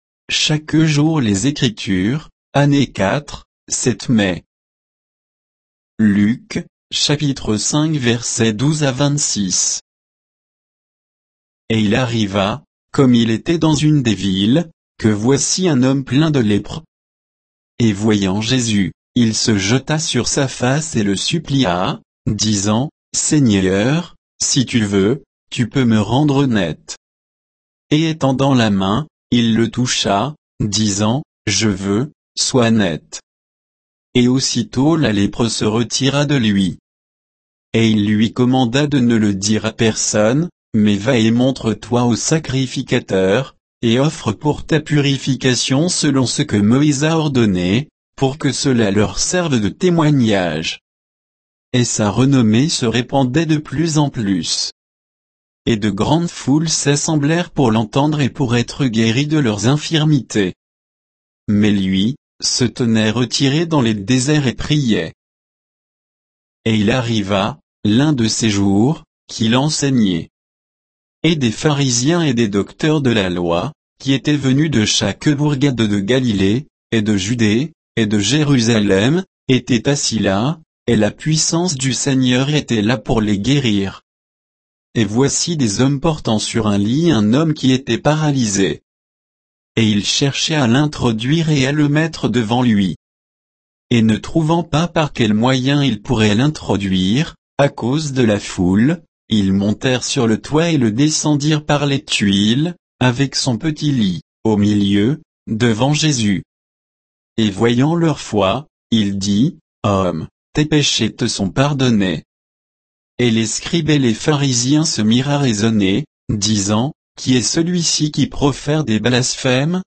Méditation quoditienne de Chaque jour les Écritures sur Luc 5, 12 à 26